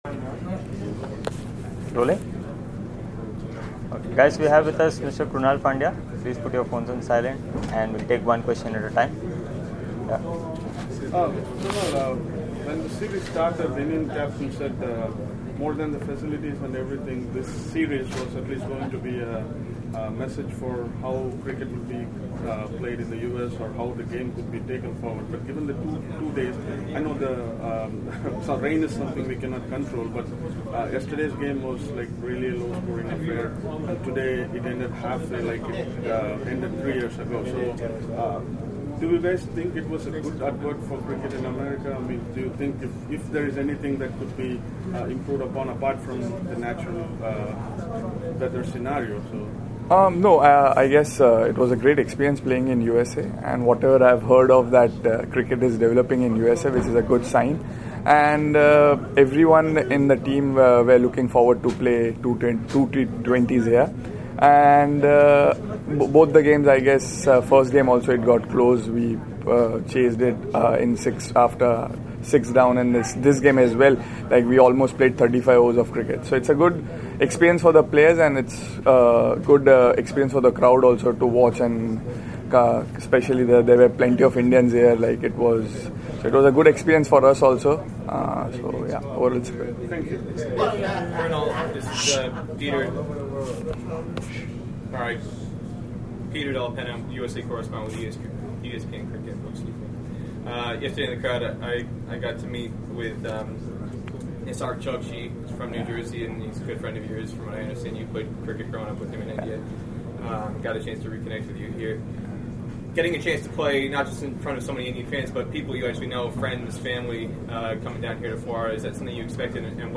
Krunal Pandya spoke to the media in Florida on Sunday after the 2nd T20I against West Indies.